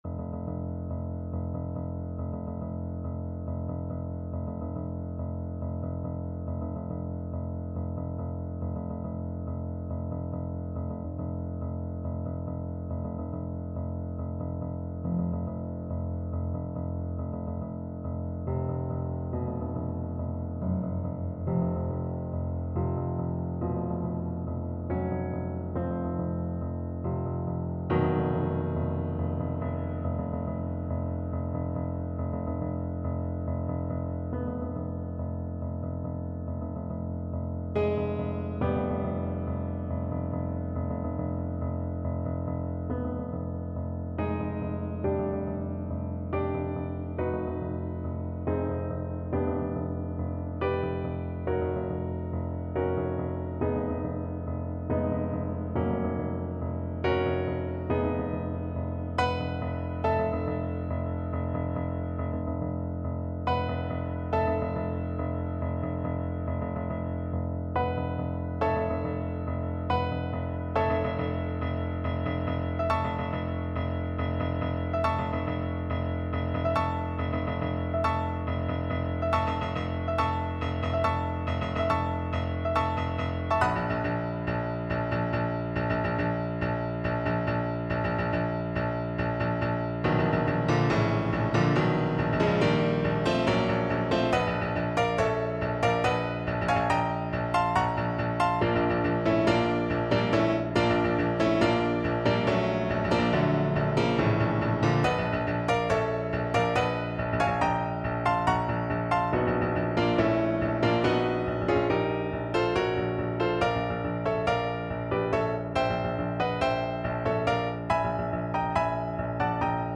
5/4 (View more 5/4 Music)
Allegro = 140 (View more music marked Allegro)
Classical (View more Classical Saxophone Music)